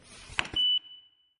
KeycardUse1.ogg